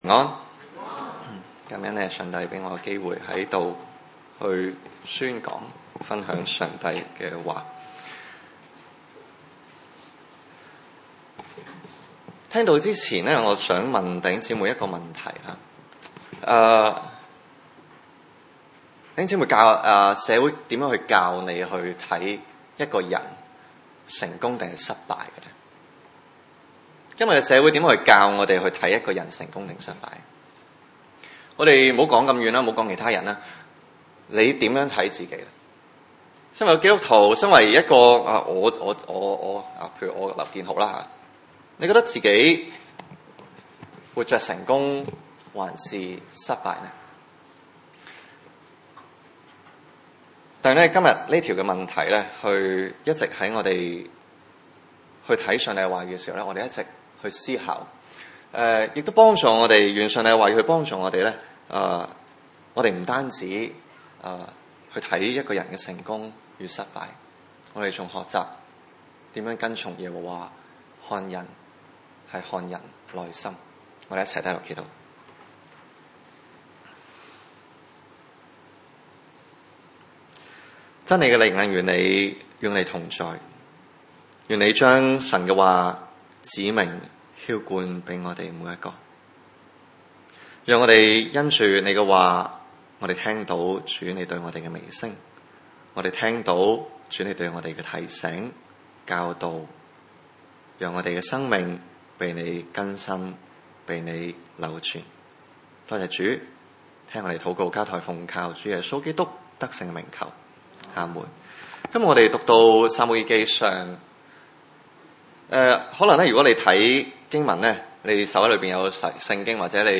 經文: 撒母耳記上 15:30-16:12 崇拜類別: 主日午堂崇拜 30.